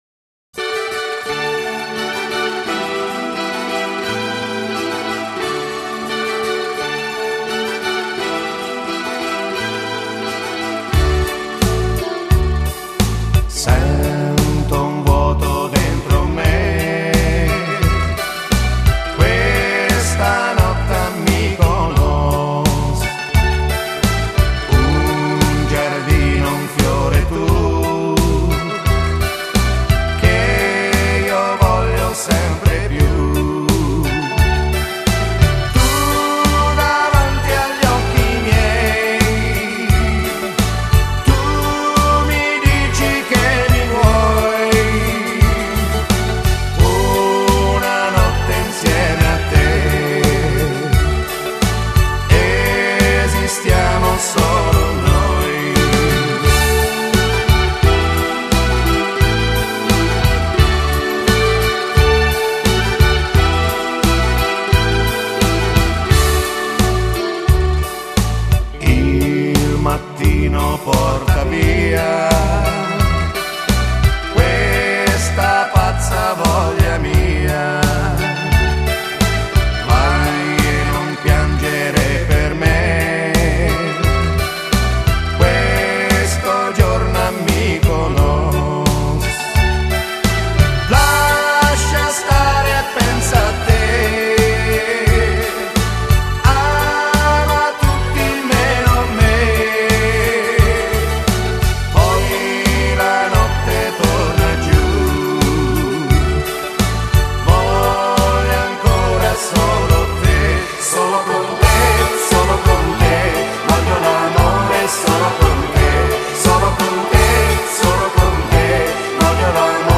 Genere: Sirtaky Fox